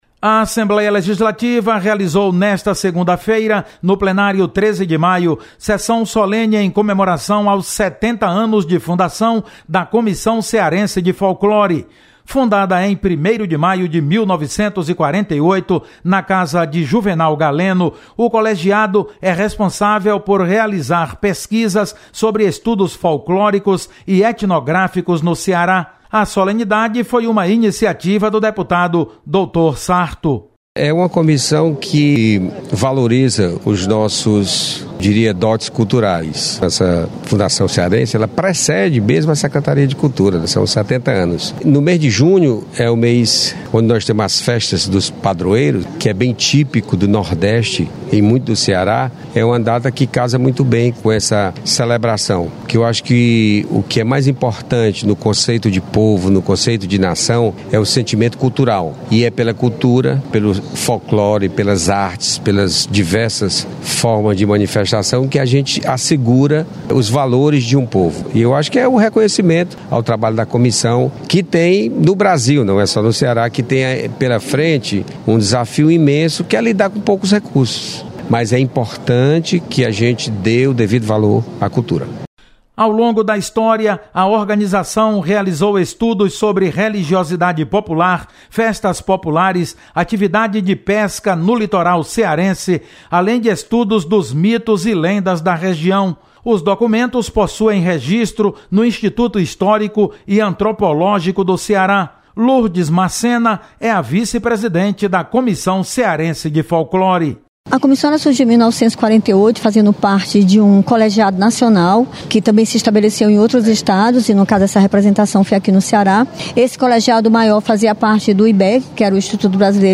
Assembleia Legislativa comemora 70 anos da Comissão Cearense de Folclore. Repórter